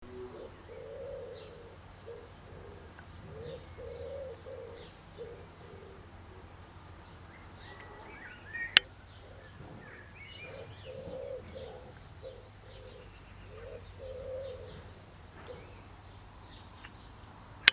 I could hear lots of the birds this afternoon in the garden, but there’s one that really evokes childhood for me. It’s nothing fancy or rare – just the sound of pigeons cooing at each other.
So it took several goes to record them without too much traffic noise in the background.